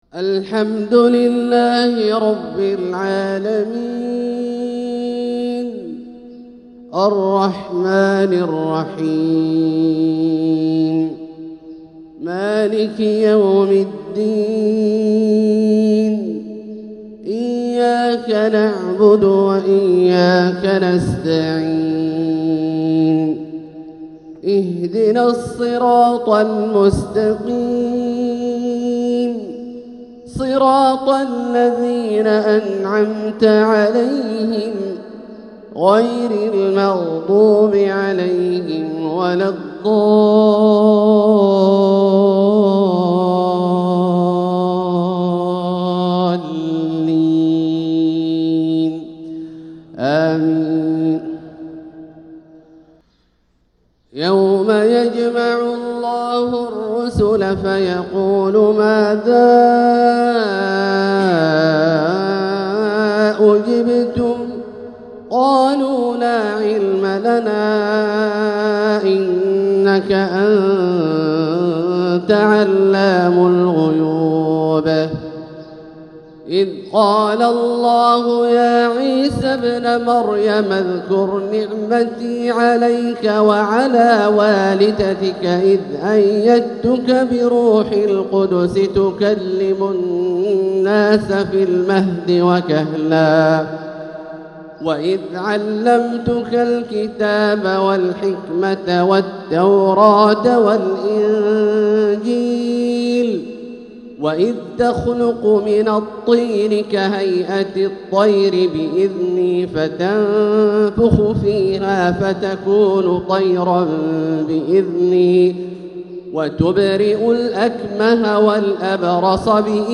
أداء روحاني بديع لأواخر سورة المائدة | فجر الأربعاء 8-7-1446هـ > ١٤٤٦ هـ > الفروض - تلاوات عبدالله الجهني